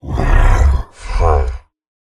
Minecraft Version Minecraft Version snapshot Latest Release | Latest Snapshot snapshot / assets / minecraft / sounds / mob / ravager / idle5.ogg Compare With Compare With Latest Release | Latest Snapshot